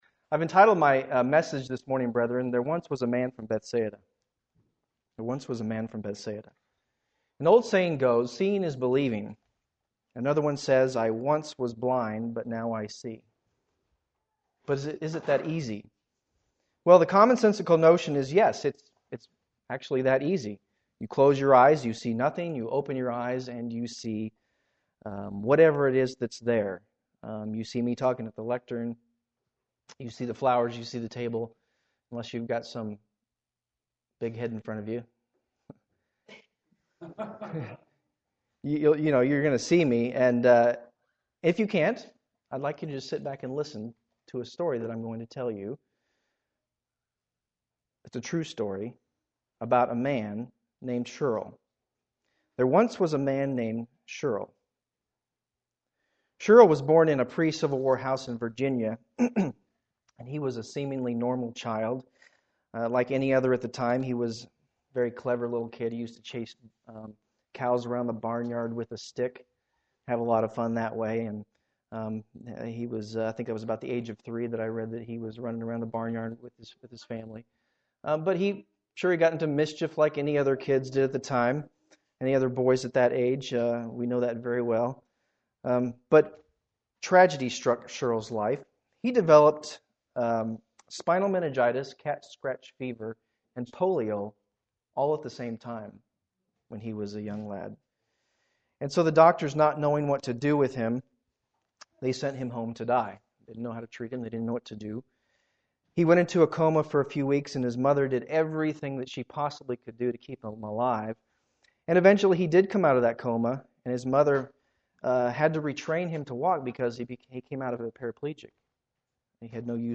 Given in Columbia - Fulton, MO
UCG Sermon Studying the bible?